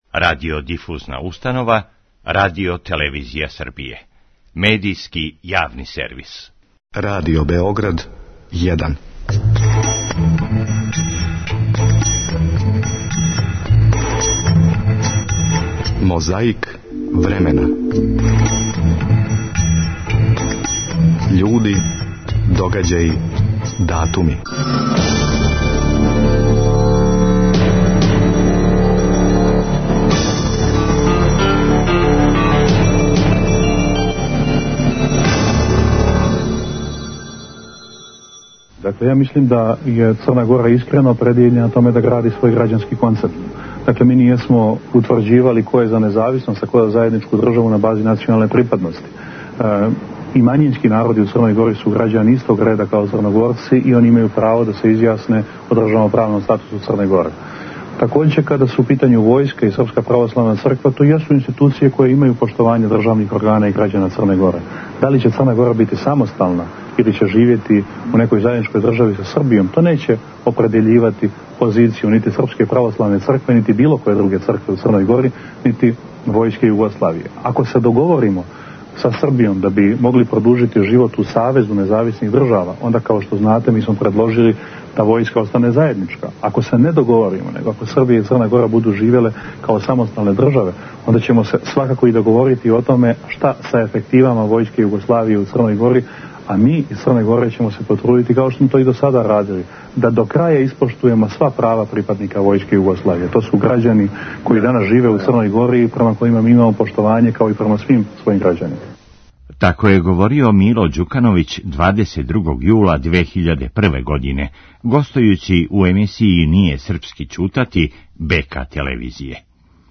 На ванредном заседању Скупштине Србије, на којем се расправљало о закону о РТС-у говорили су многи па и Зоран Ђинђић и Томислав Николић.